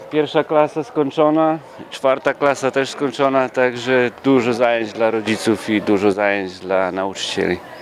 Z mikrofonem i kamerą byliśmy w Szkole Podstawowej nr 3 w Ełku.